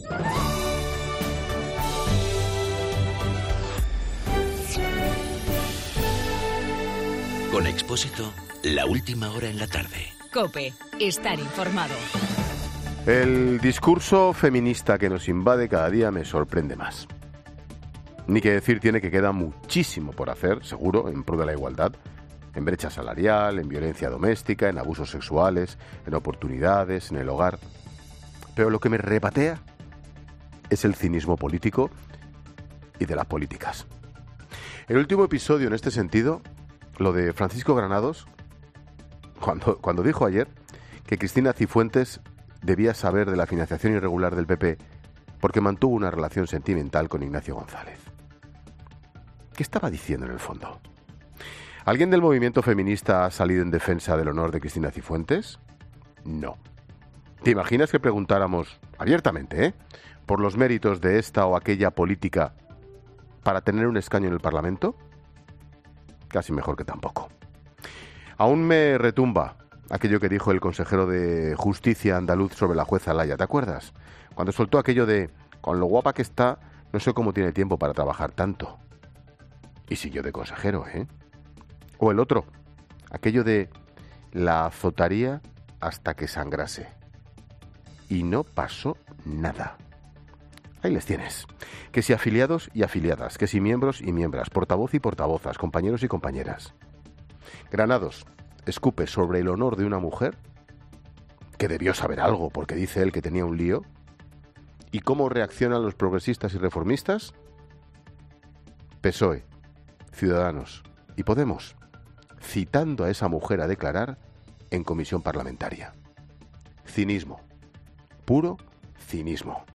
AUDIO: El comentario de Ángel Expósito sobre el feminismo oportunista.
Monólogo de Expósito Expósito a las 17h: "Cinismo y machismo.